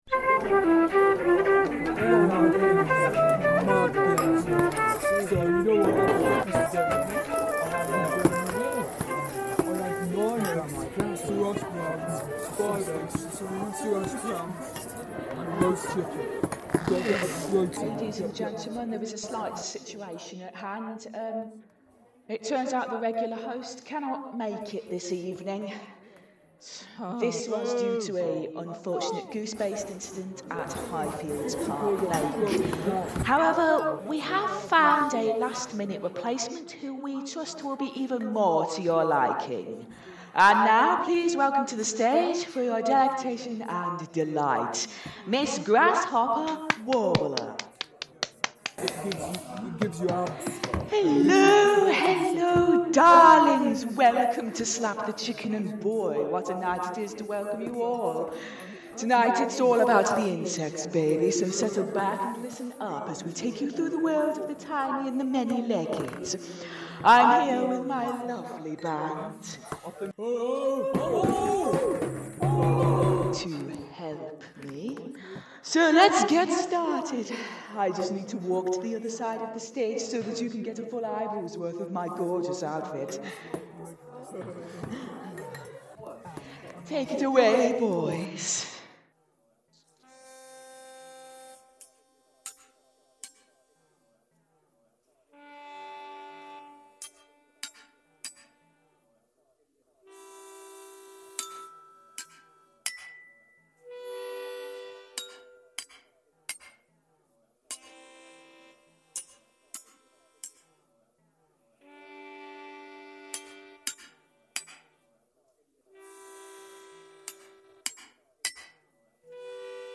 Due to a mystery goose attack putting the host out of action, today's exploration of insects will be taken by the noted singer, Miss Grasshopper Warbler and her wonderfully competent jazz band. Fresh from a sold out run at The Princess Theatre, Hunstanton, Miss Warbler will be belting out the hits in her inimitable, dazzling style.